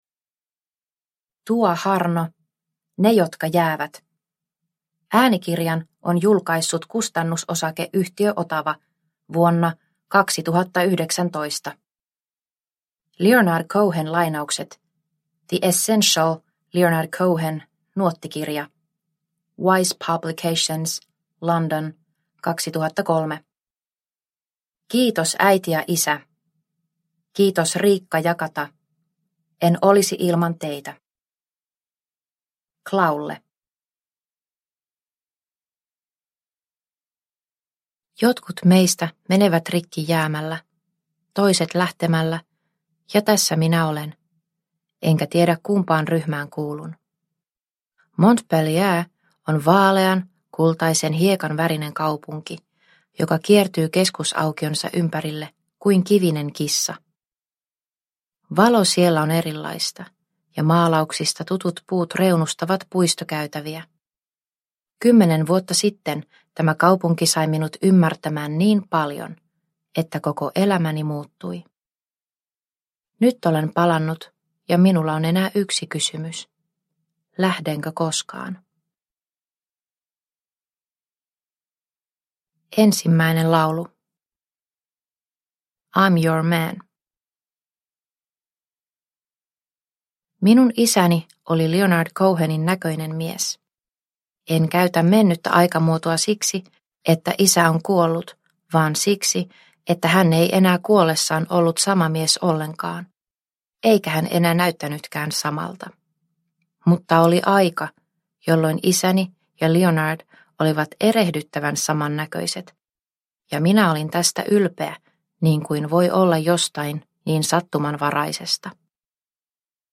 Ne jotka jäävät – Ljudbok – Laddas ner